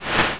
Sound Effects (efx)
stereo sounds developed for mouse interaction on a Macintosh